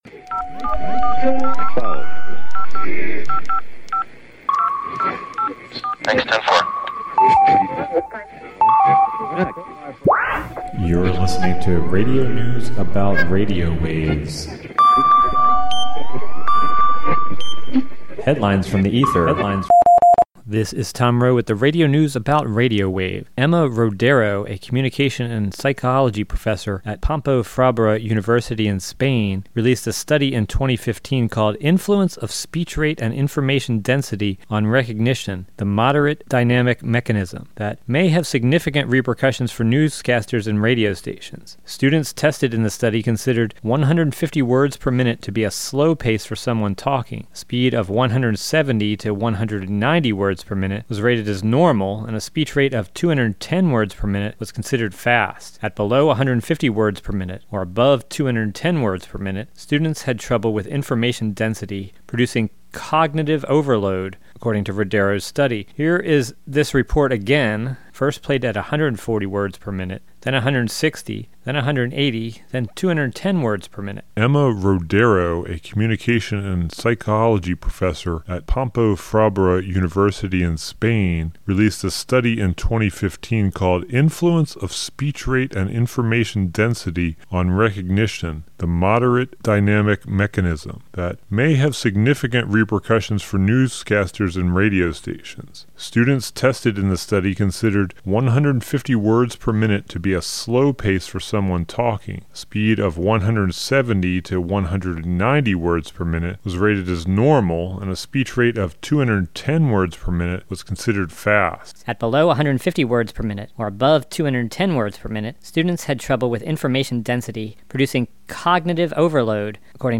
So here is this report again, first played at 140 words per minute, then 160, then 180, then 210 words per minute.